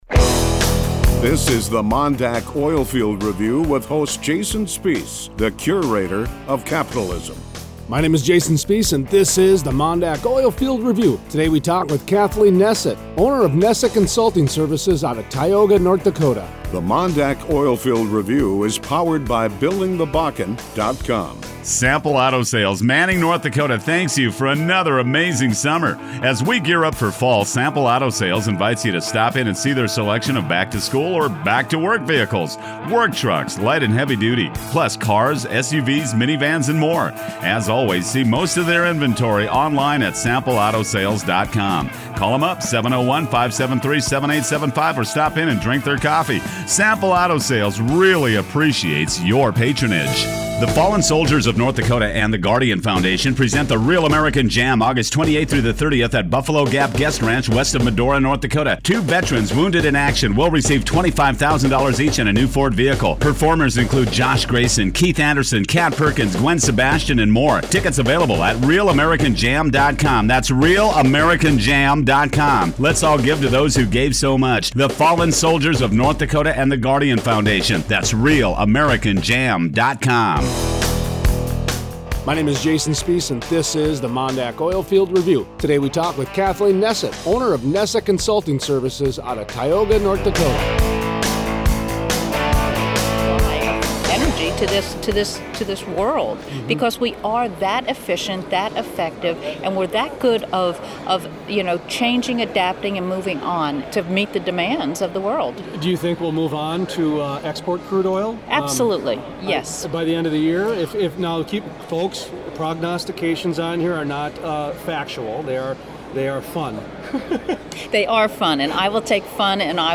Tuesday 8/4 Interview